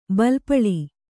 ♪ balpaḷi